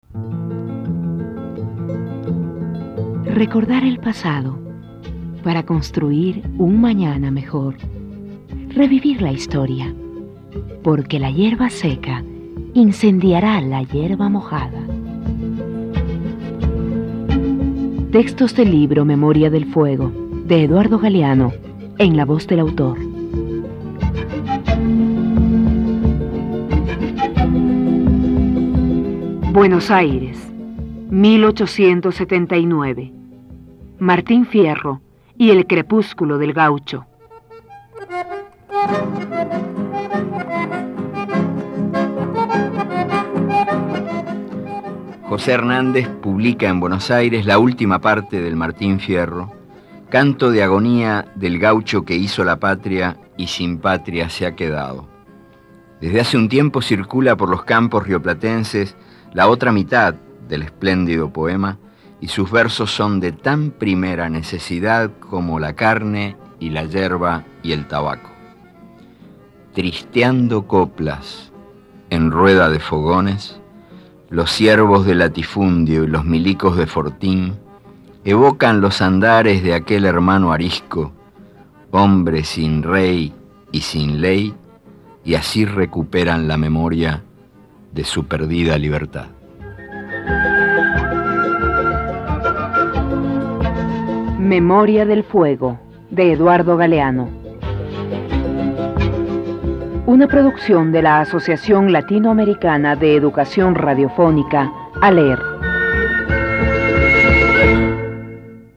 Relato en prosa perteneciente a Memoria del fuego (II). Las caras y las máscaras, segundo libro de la trilogía del escritor Eduardo Galeano que narra la historia de América Latina hasta el siglo XX.
Este archivo de sonido ofrece la lectura del texto en la voz de su autor.